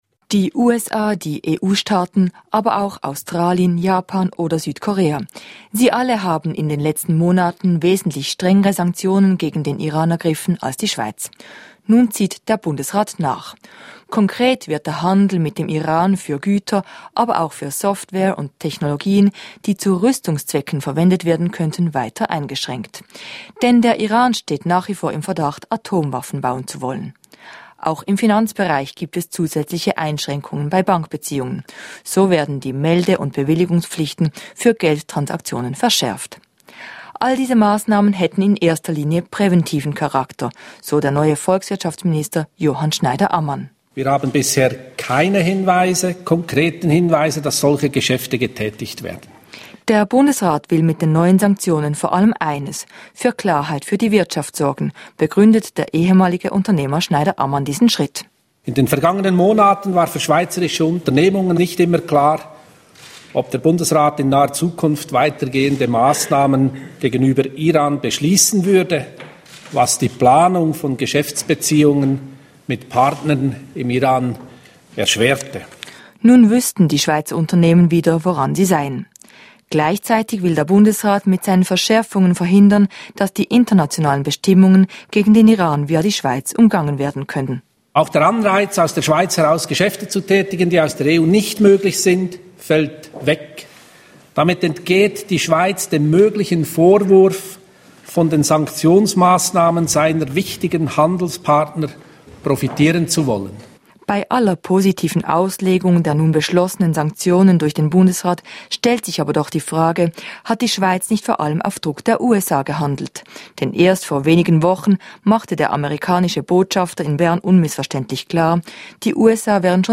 Bericht aus dem Bundeshaus